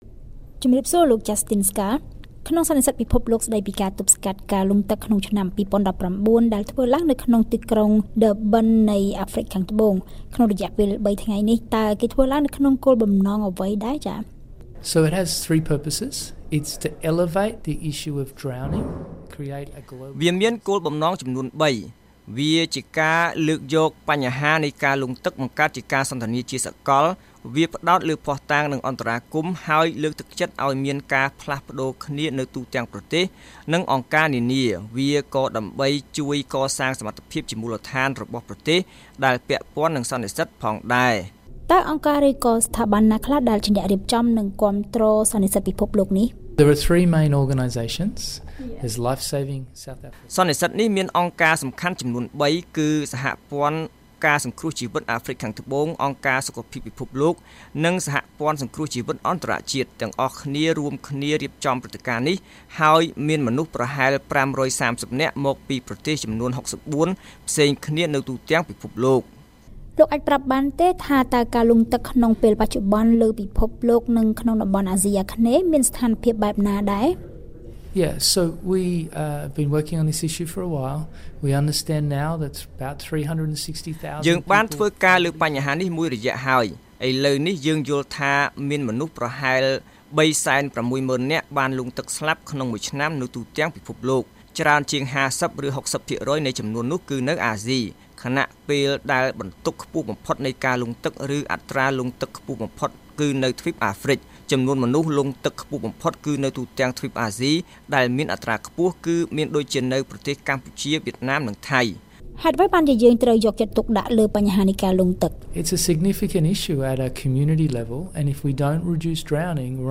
បទសម្ភាសន៍ VOA៖ សន្និសីទពិភពលោកស្ដីពីការបង្ការការលង់ទឹករំពឹងថាការស្លាប់ដោយលង់ទឹកនឹងត្រូវបានកាត់បន្ថយ